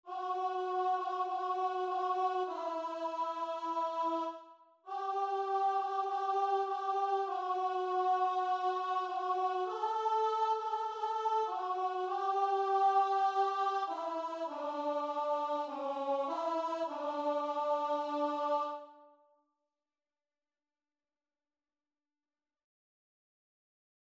4/4 (View more 4/4 Music)
Classical (View more Classical Guitar and Vocal Music)